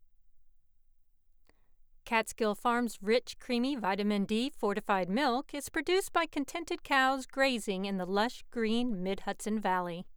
Audio Sound Test
My home studio is in a closet and I’m using blankets and towels for sound absorbtion; fortunately, I live in a quiet area, so I don’t have a lot of outside sounds coming in. I recorded the sample with a Rode NT1 mic and Scalette Solo 3rd gen AI.
It has a very odd noise signature.
This should sail right through ACX technical testing.